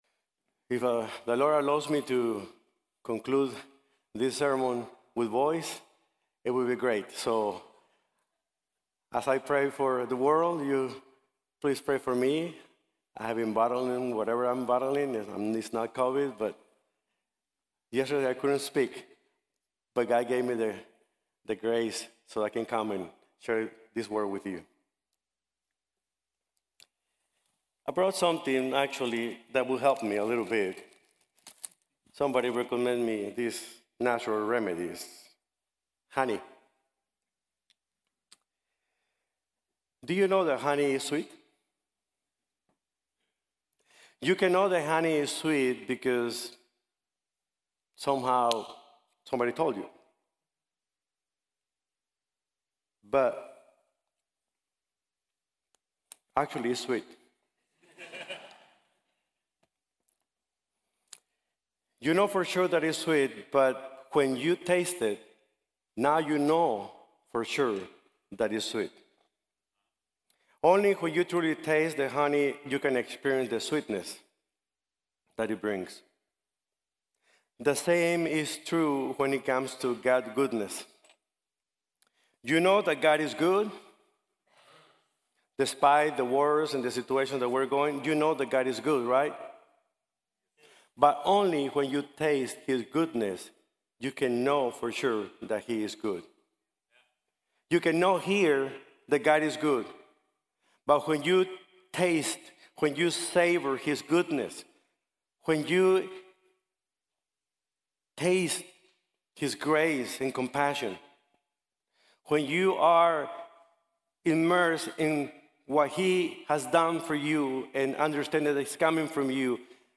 Colossians 3:1-11 | Sermon | Grace Bible Church